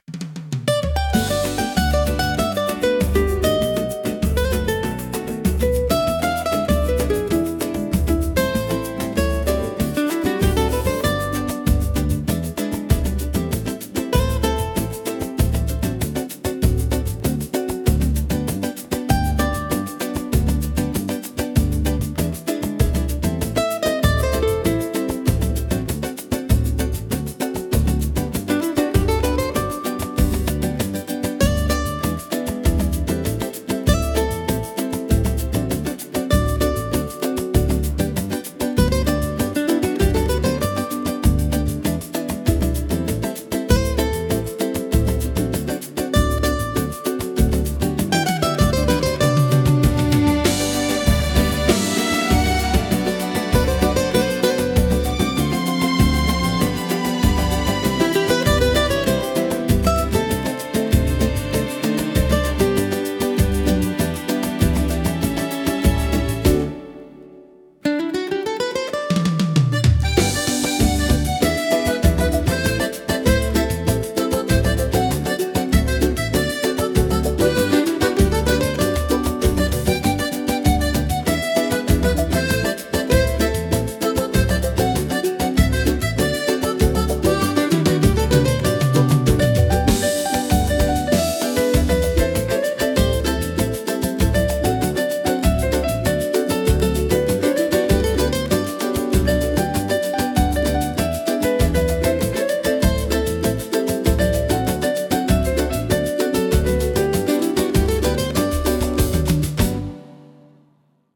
instrumental 7